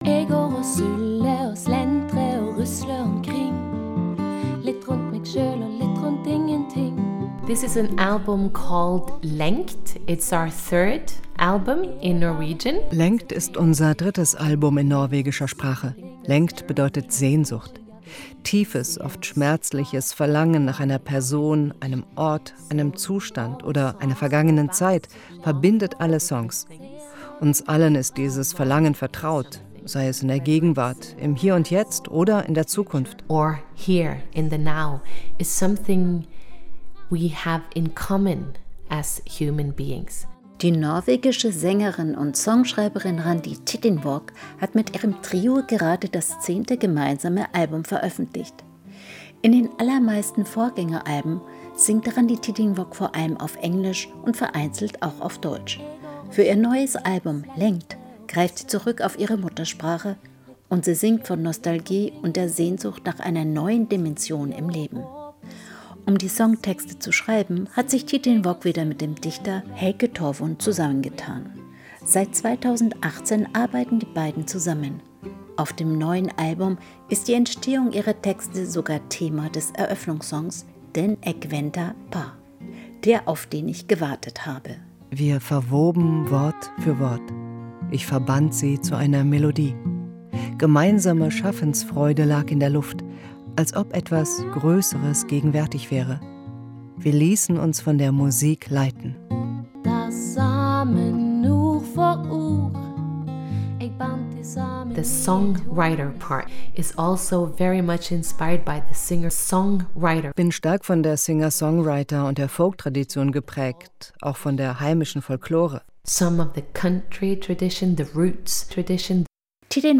Portrait